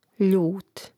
ljȗt ljut